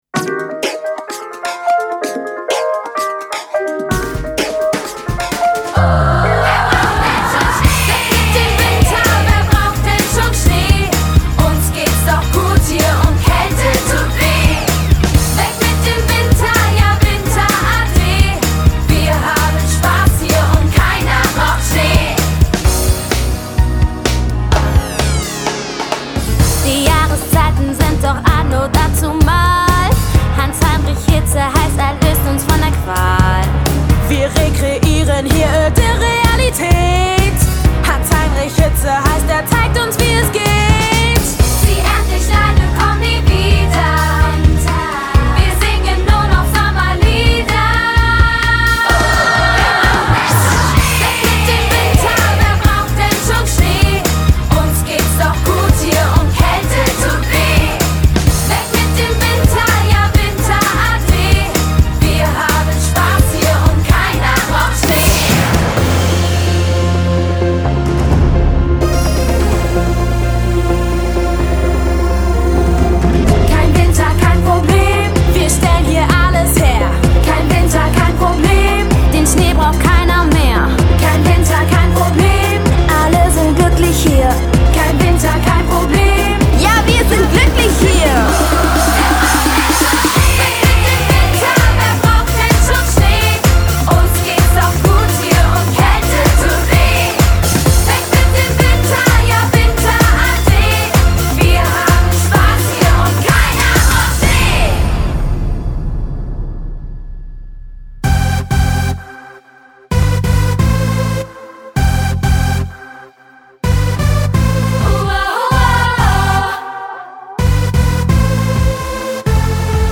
Snippet 1 – Party-Songs